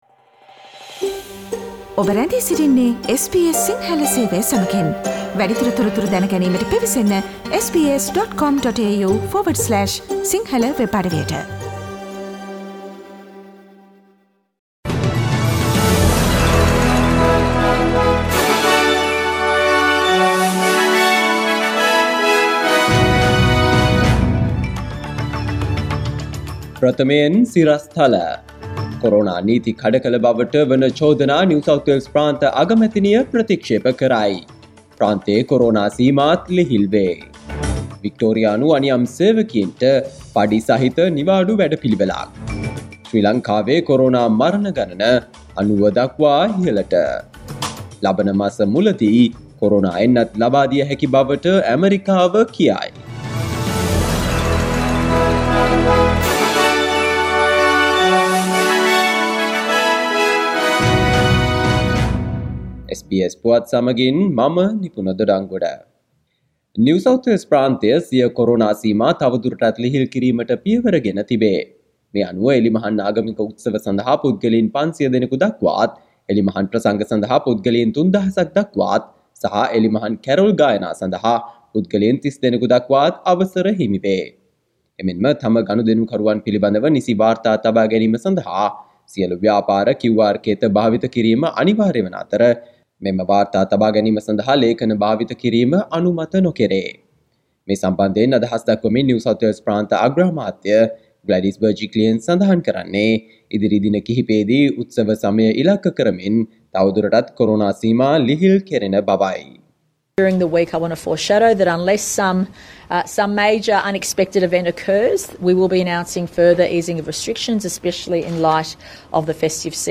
Daily News bulletin of SBS Sinhala Service: Tuesday 24 November 2020
Today’s news bulletin of SBS Sinhala radio – Tuesday 24 September 2020.